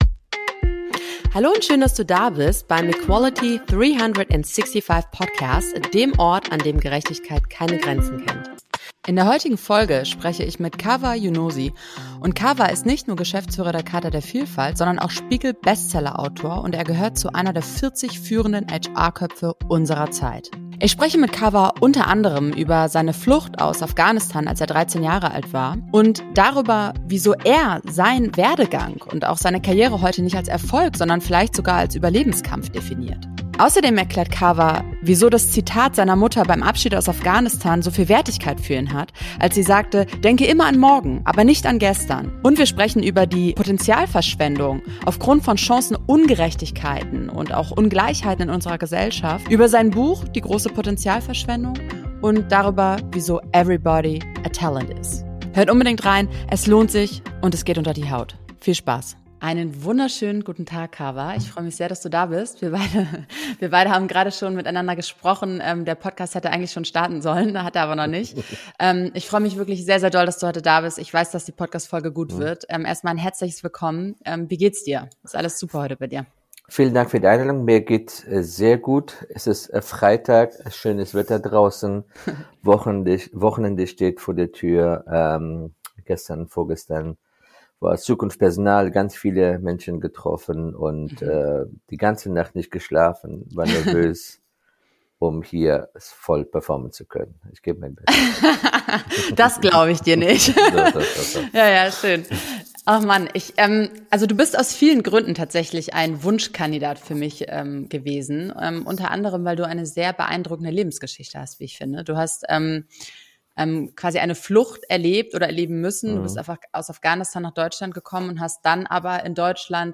Im Podcast Interview